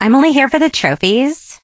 emz_start_vo_04.ogg